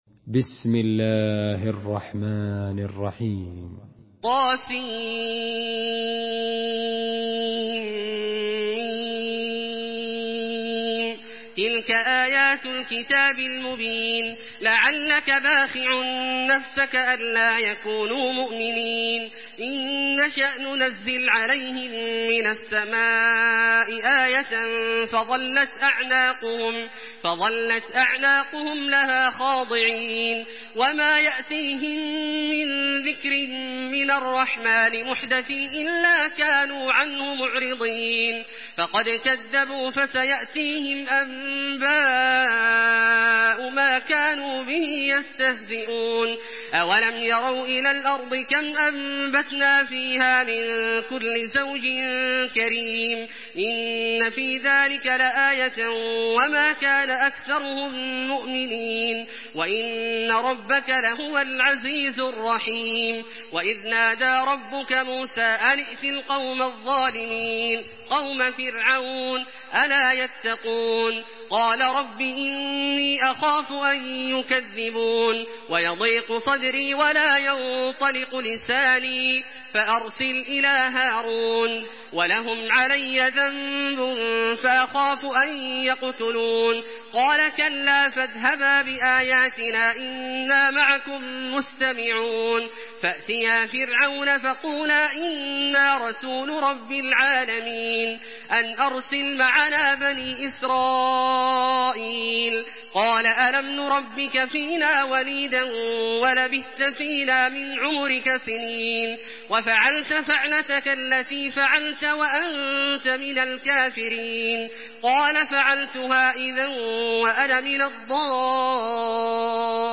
Surah আশ-শু‘আরা MP3 in the Voice of Makkah Taraweeh 1426 in Hafs Narration
Murattal